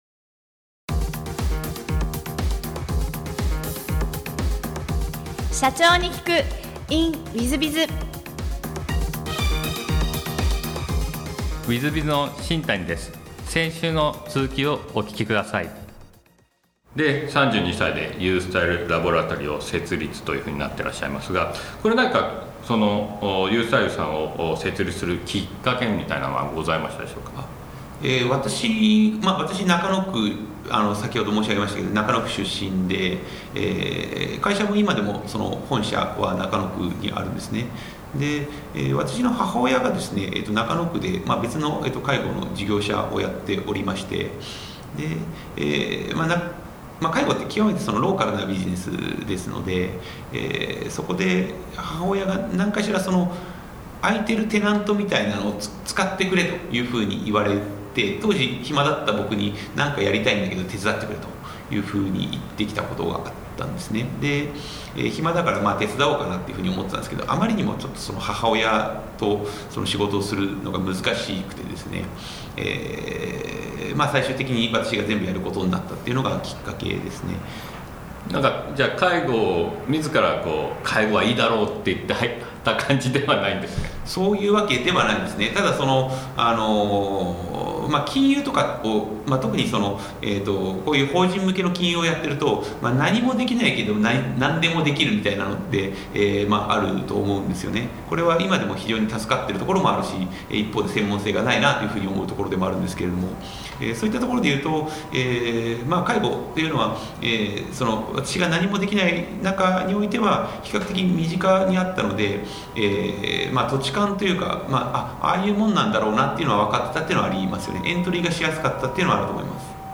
訪問介護ビジネスのフランチャイズを展開し、年商100億円の企業へと成長させたエピソードから、経営のヒントが得られます。ぜひ、インタビューをお聞きください。